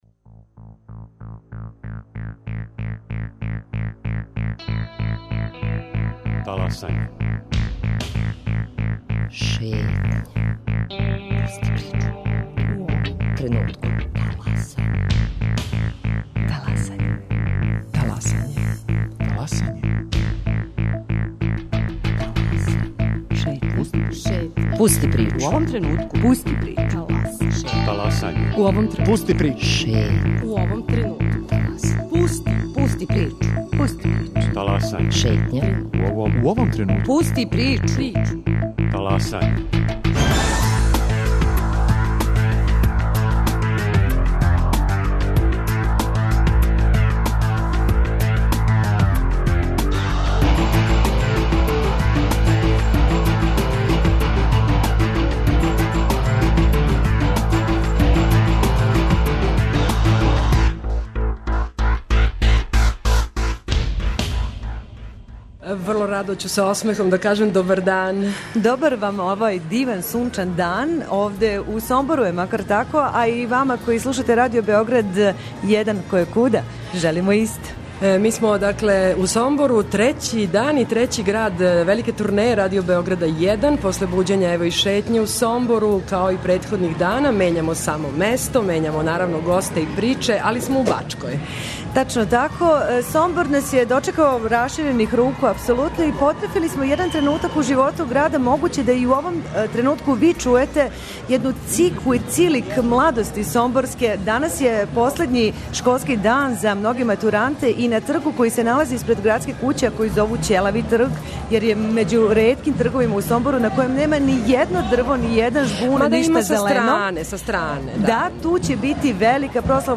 уживо из Сомбора